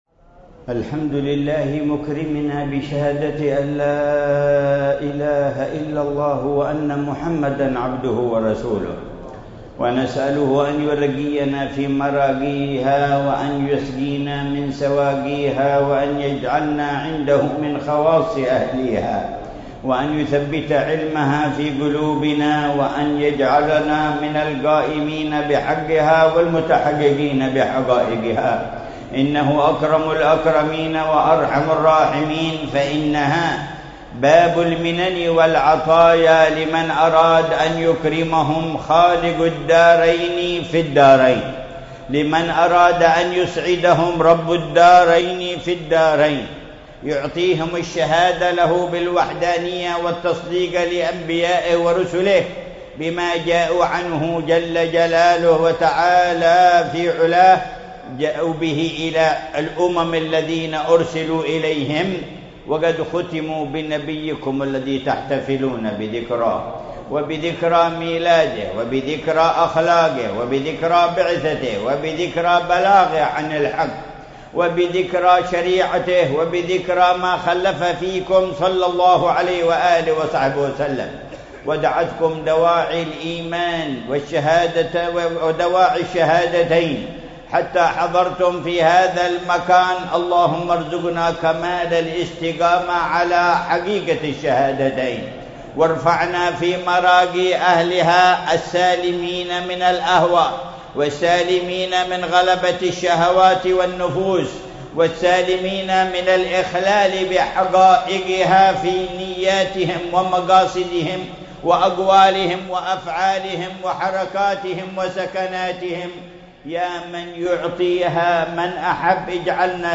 محاضرة العلامة الحبيب عمر بن محمد بن حفيظ في جامع الروضة بمدينة المكلا، بساحل حضرموت، ليلة السبت 14 ربيع الثاني 1445هـ بعنوان: